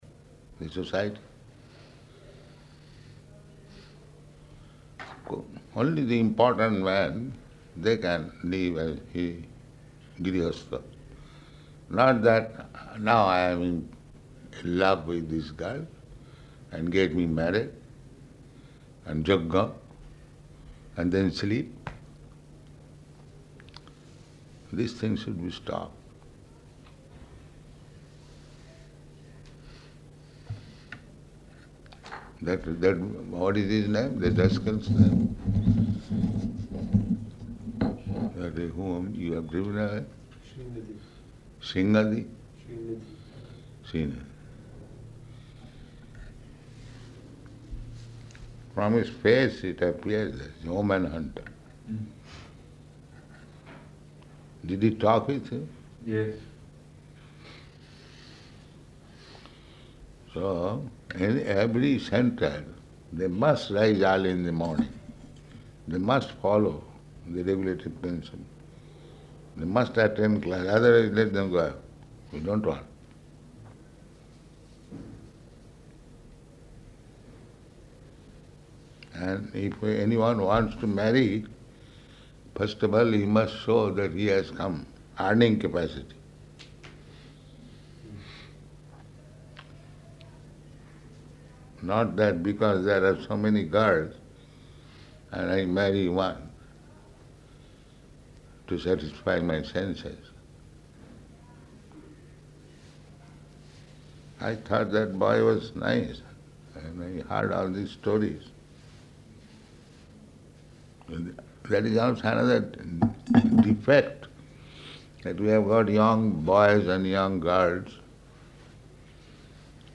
Room Conversation
Location: Delhi